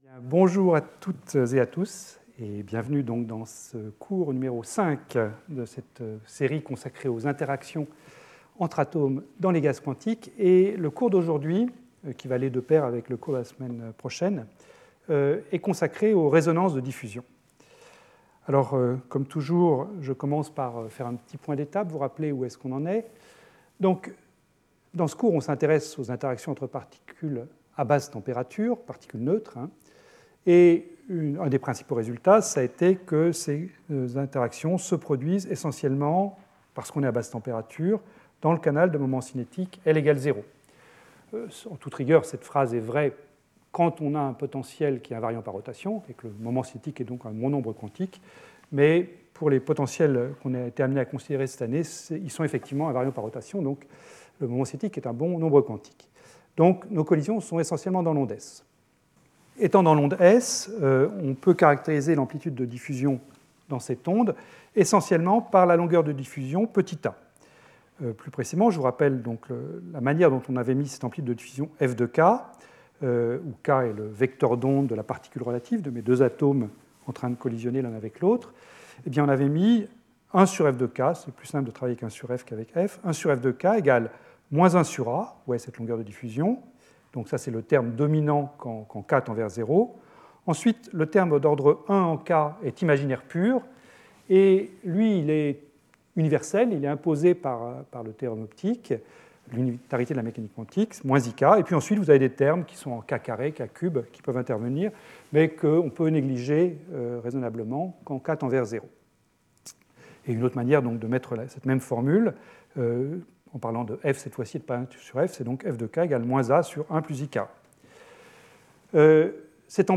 Intervenant(s) Jean Dalibard Professeur du Collège de France